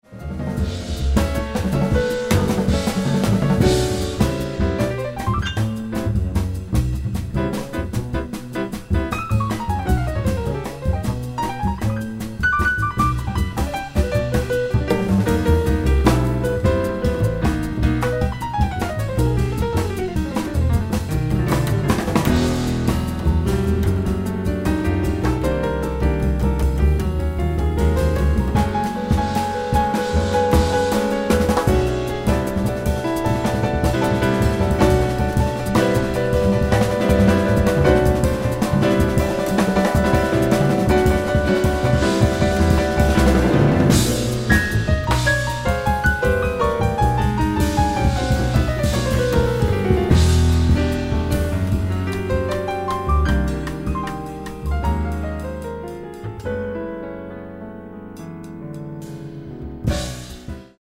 drums
acoustic bass